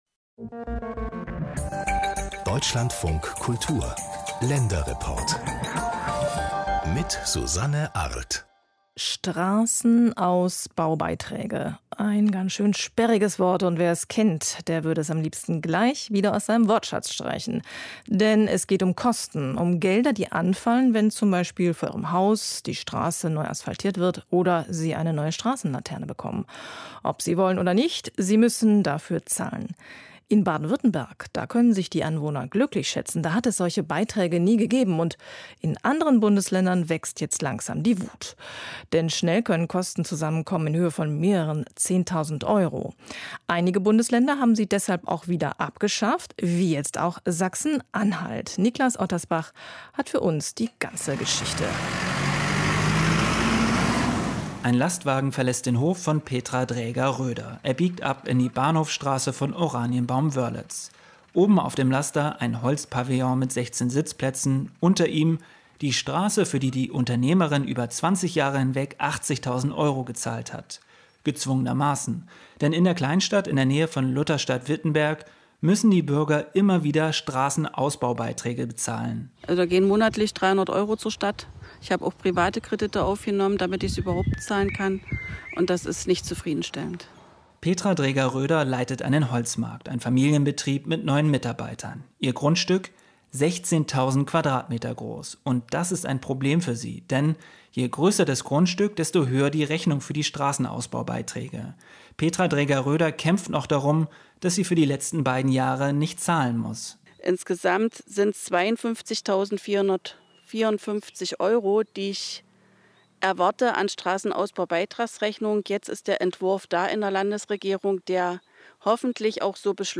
Abschaffung der Straßenausbaubeiträge: Wie Bürgerinitiativen sich vernetzen lautet der Titel eines Beitrages vom 24. August 2020 im Länderreport des Deutschlandfunks aus Sachsen-Anhalt.